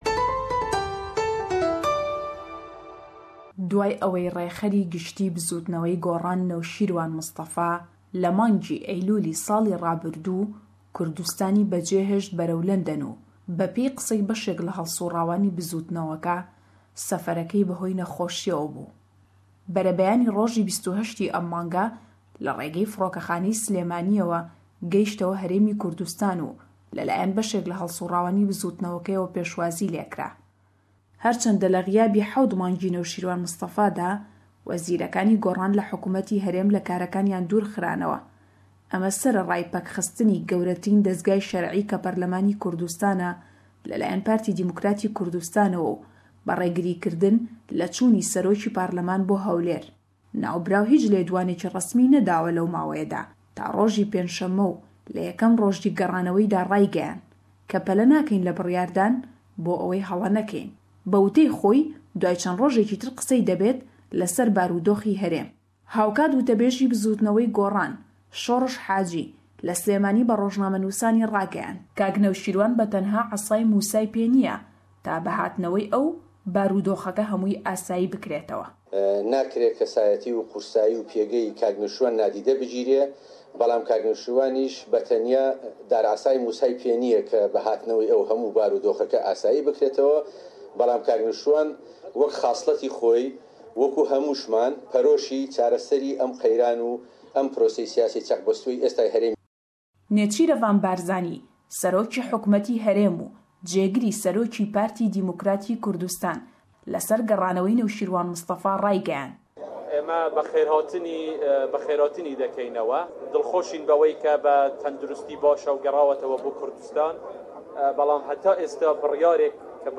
ji Slêmaniyê li ser nûçeyên herî dawîn ji HK û Îraqê û kêsheyên berdewam di nav berî partiyên siyasî de.